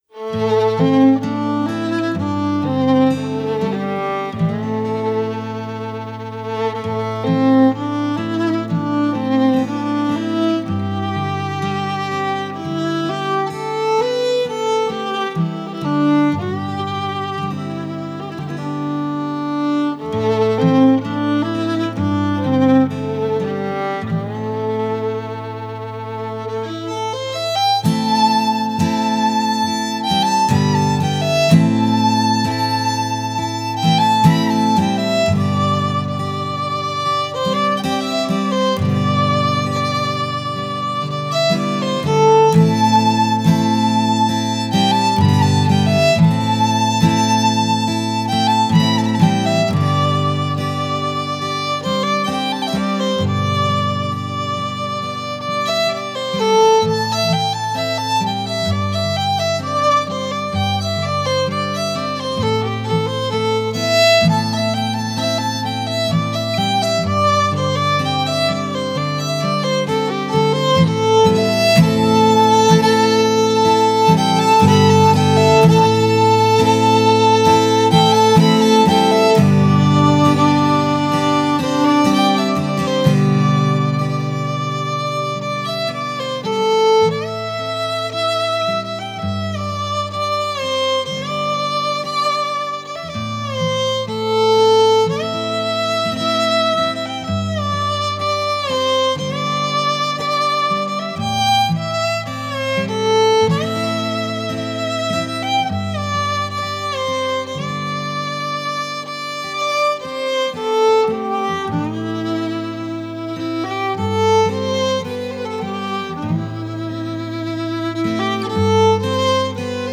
Genre: Alternative Folk, Singer/Songwriter, Country